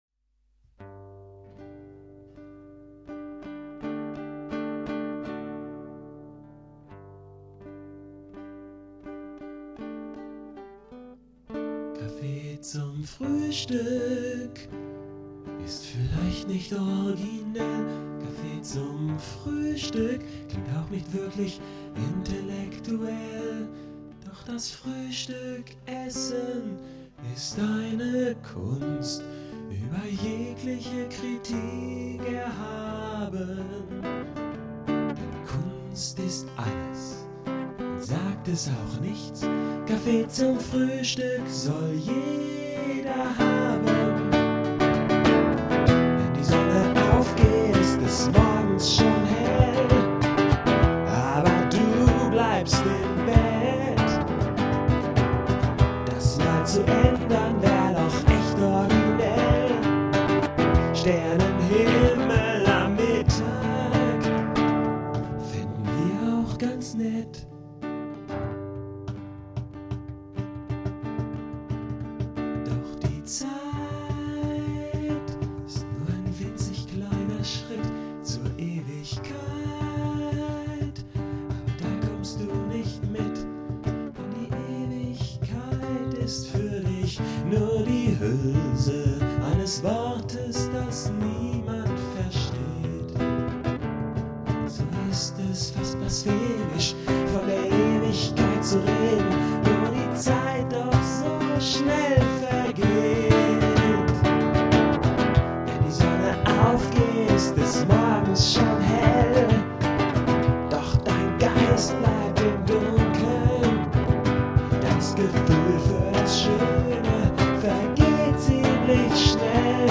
vocals and rhythm guitar
lead & rhythm guitar, cajón and bass